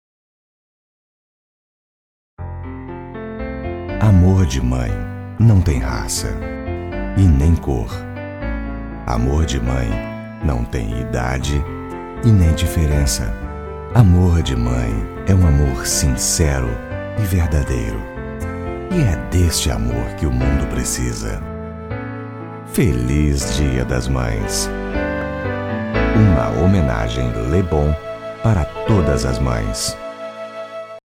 Sprechprobe: eLearning (Muttersprache):
Portuguese voice over artist.